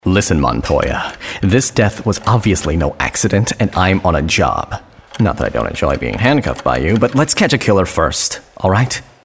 English (American)
Natural, Friendly, Warm